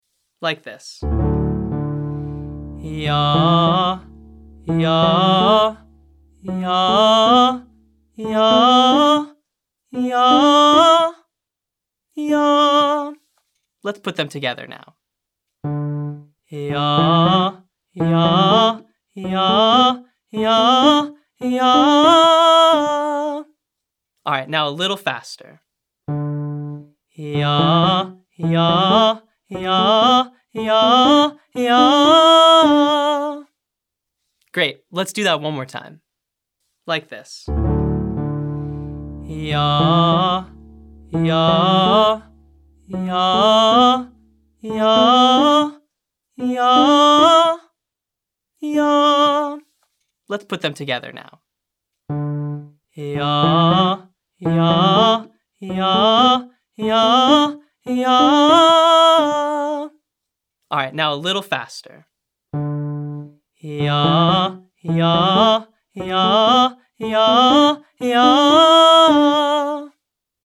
• Descending pentatonic 3-note riffs
• Ascending pentatonic 3-note riffs